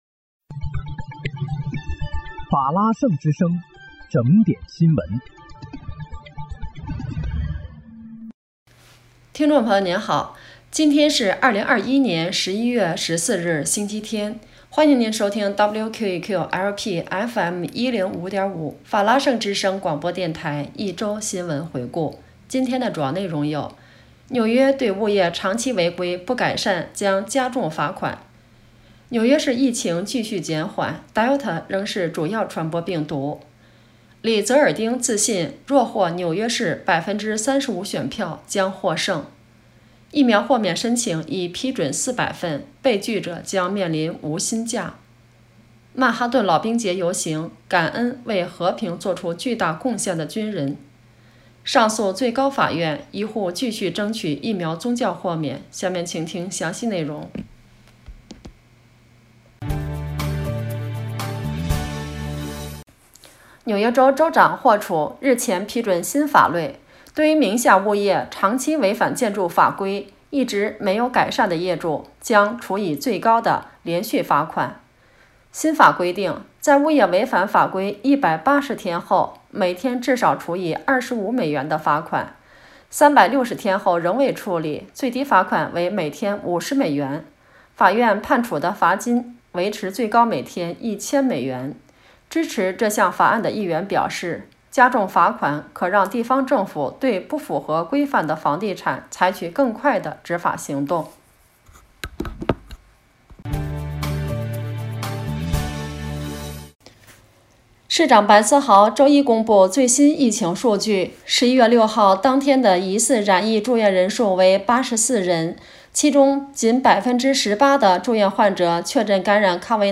11月14日（星期日）一周新闻回顾